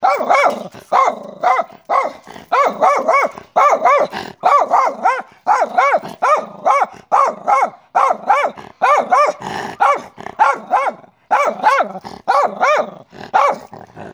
dog-dataset
adult_dog_0038.wav